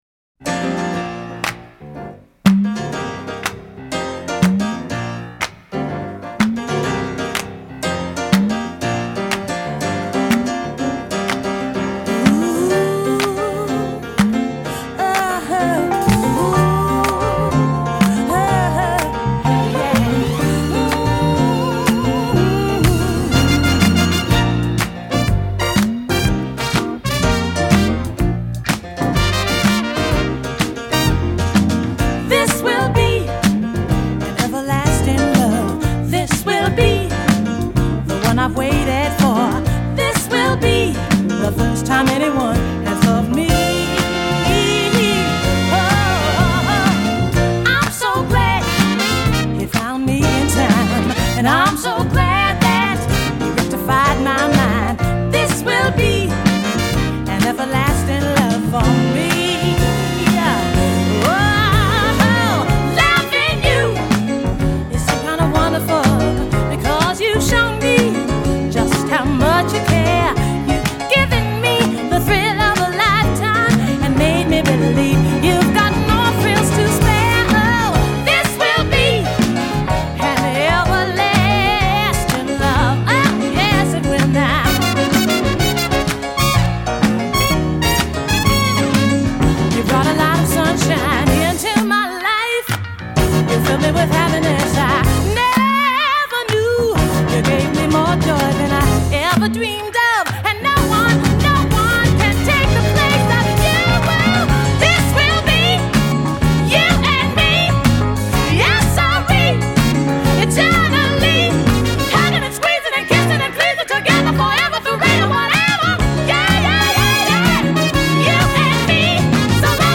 1975   Genre: Pop   Artist